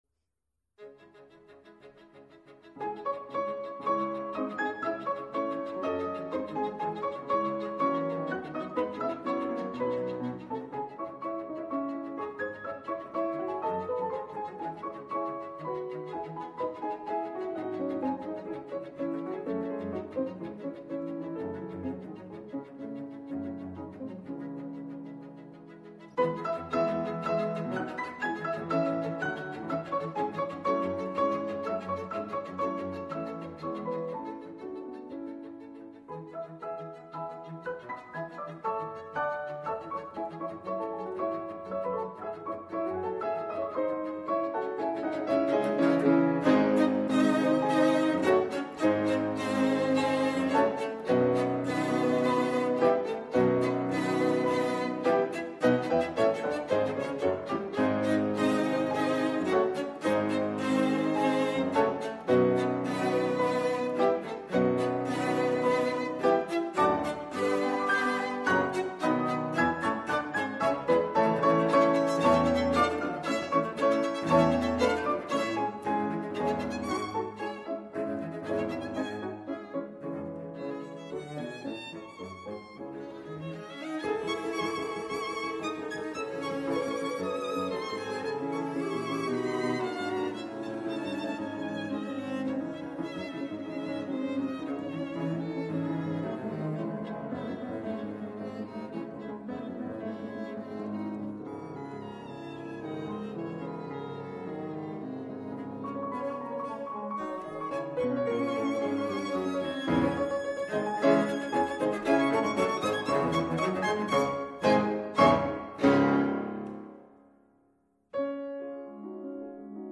Dvoraks f minor  trio
violin
cello
piano